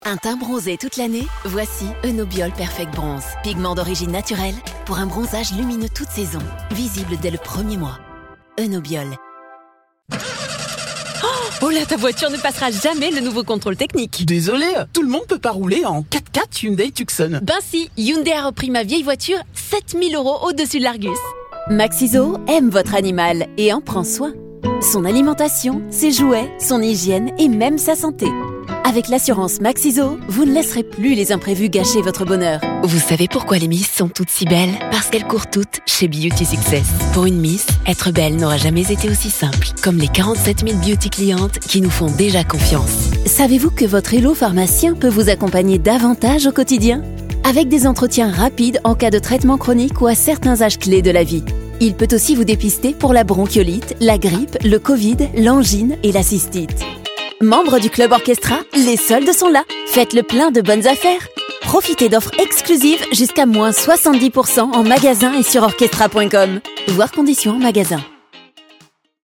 Tief, Verspielt, Vielseitig, Sanft, Corporate
Kommerziell